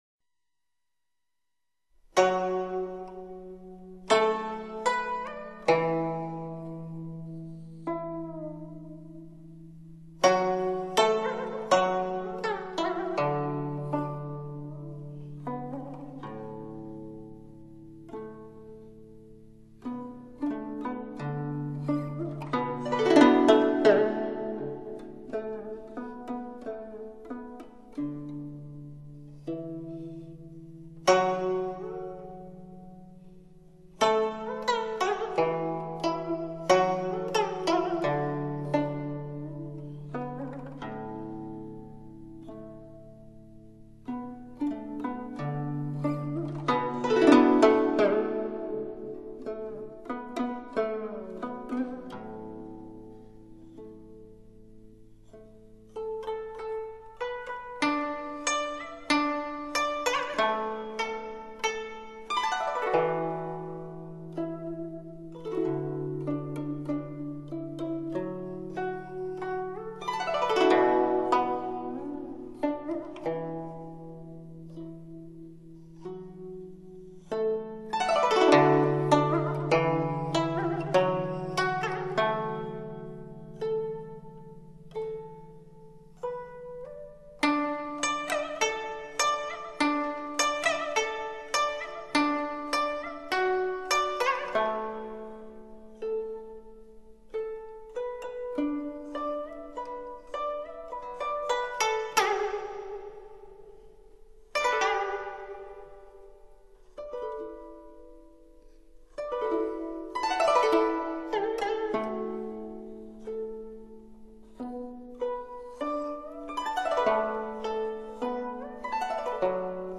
演奏：国内顶尖古筝名家